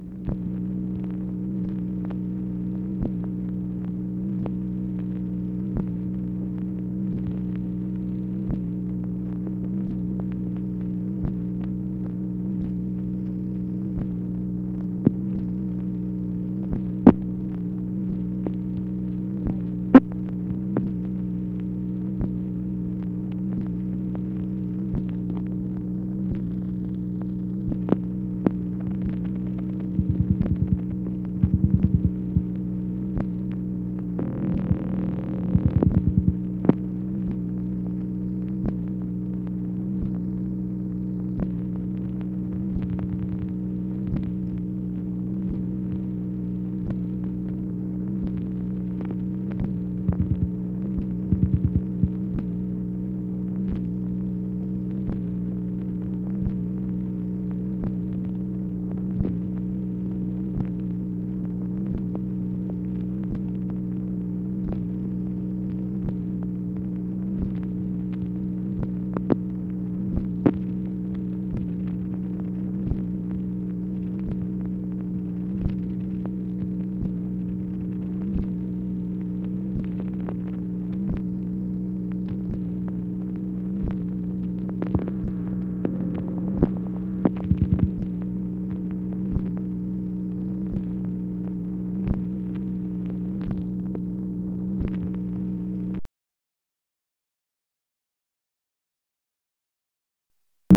MACHINE NOISE, January 31, 1964
Secret White House Tapes | Lyndon B. Johnson Presidency